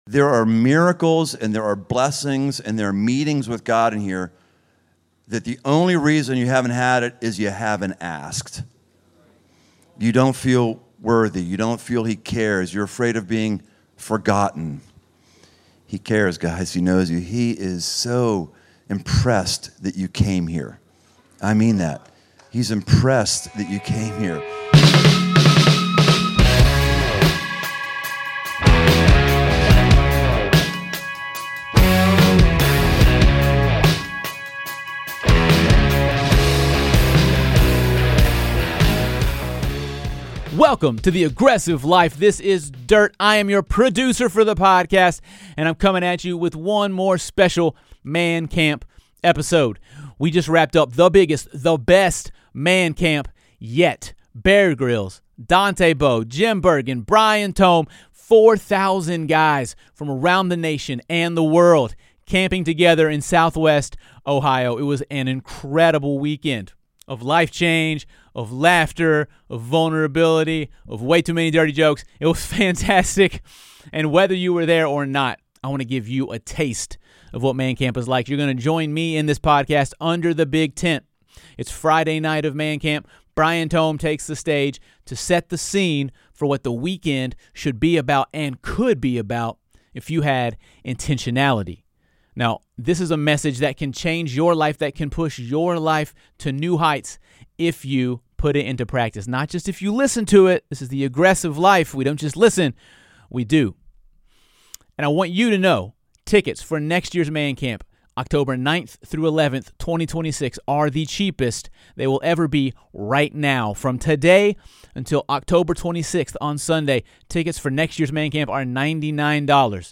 This teaching, recorded live under the big tent on Friday evening, set the stage for all that God would do over the weekend. Initiative leads to breakthrough because it gets God’s attention—at camp, at home, and everywhere in between.